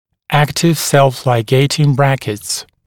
[‘æktɪv self-laɪˈgeɪtɪŋ ‘brækɪts][‘эктив сэлф-лайˈгейтин ‘брэкитс]активные самолигирующиеся брекеты